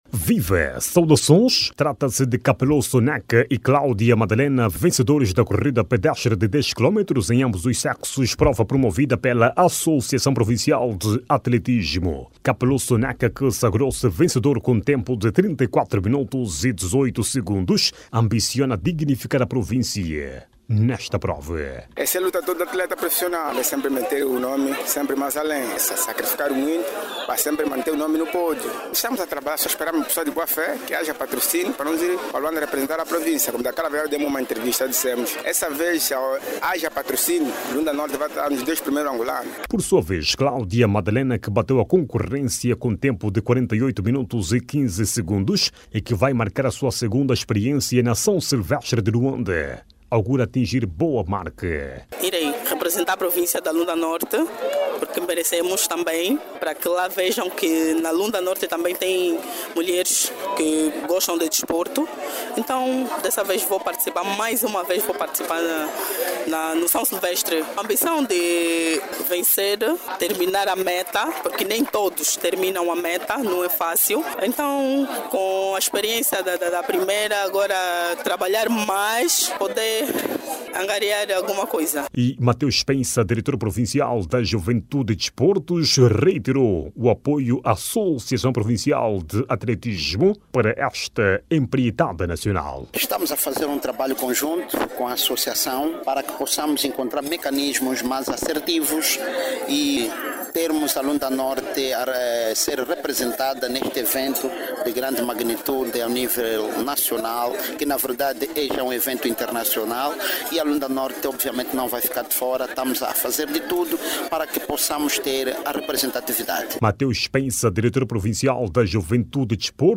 a partir do Dundo